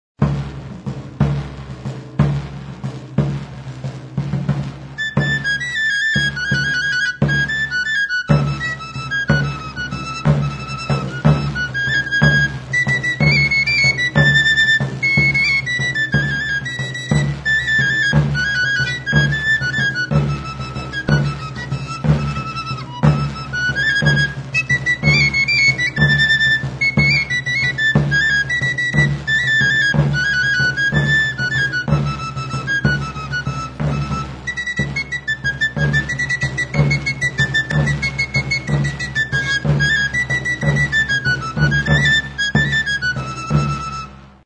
Tamborilero de Huelva
Membranophones -> Beaten -> Stick-beaten drums
SEGUIDILLA.
TAMBORIL ; TAMBOR ROCIERO